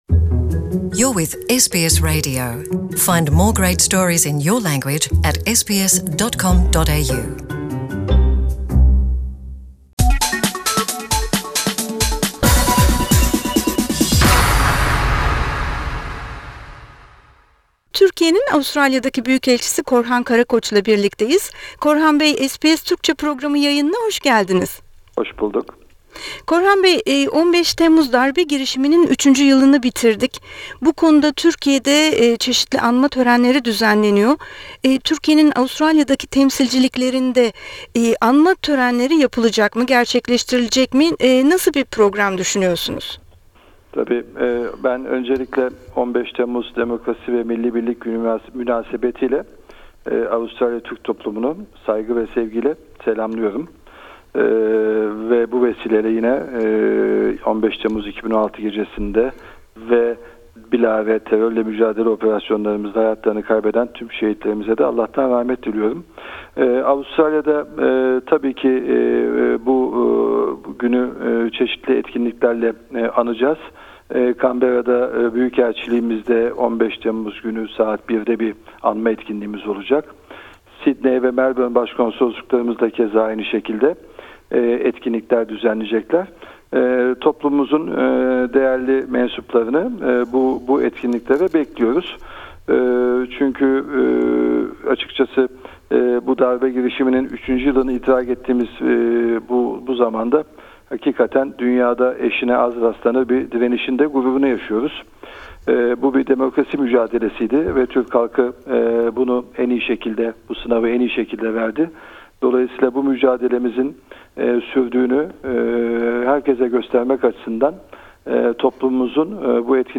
Korhan Karakoç, SBS Türkçe'yle konuştu.